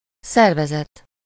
Ääntäminen
US : IPA : /ˌɔɹ.ɡə.nɪˈzeɪ.ʃən/